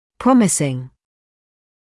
[‘prɔmɪsɪŋ][‘промисин]многообещающий; инговая форма от to promise